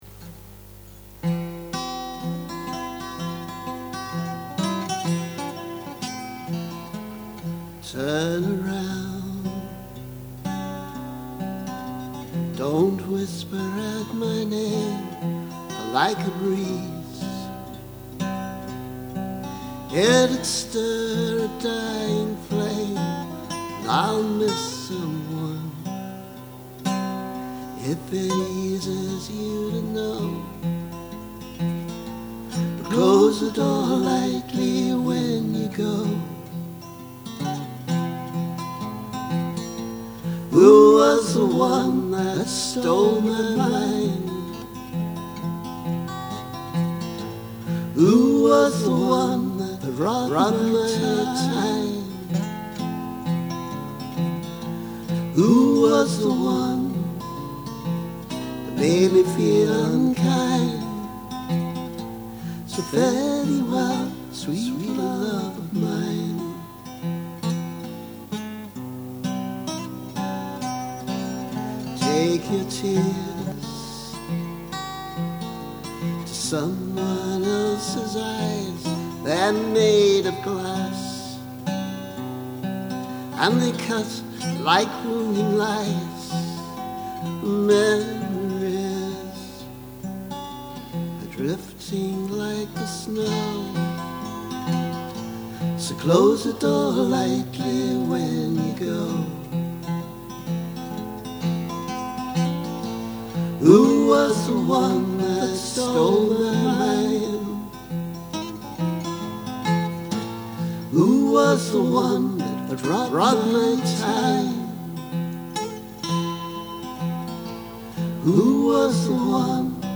And I have yet to double track my vocals live.